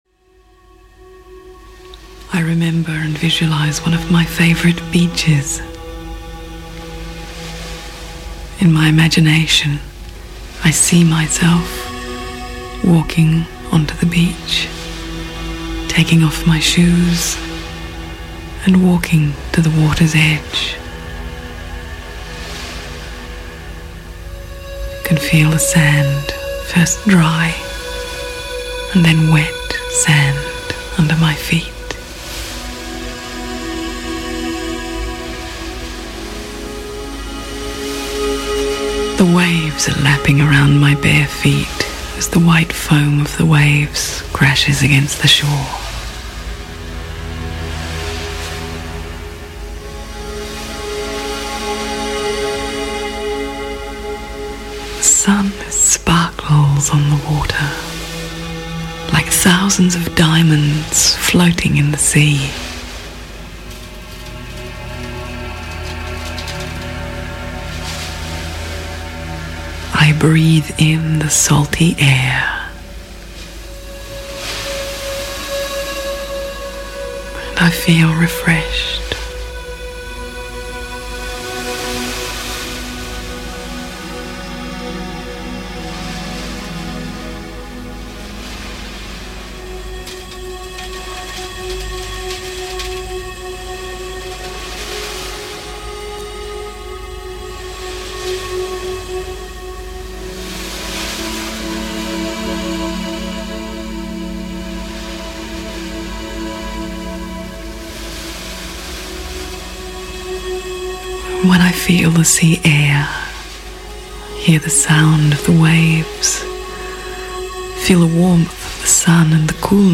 Relaxation and meditation audiobook.